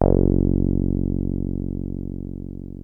303 F#1 5.wav